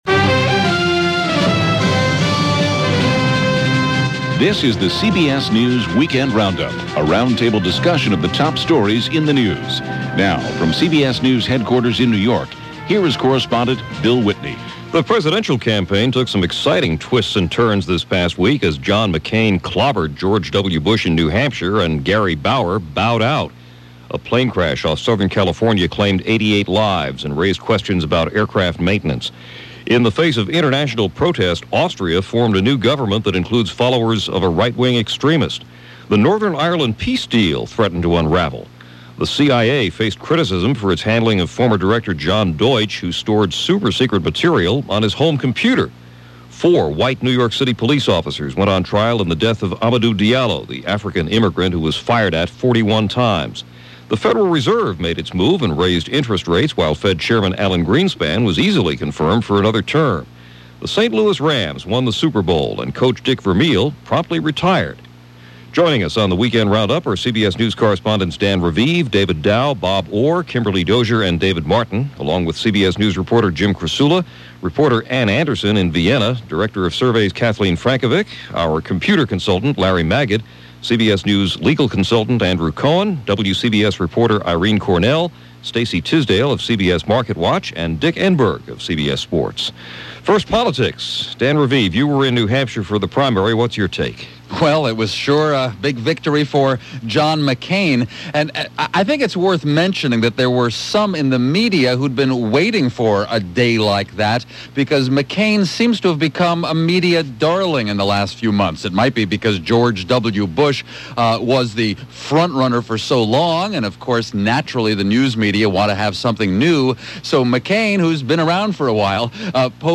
CBS Weekend Roundup
February 5, 2000 – a Saturday, and a wrapup of the week’s important stories from CBS News – starting with the New Hampshire Republican and Democratic primaries.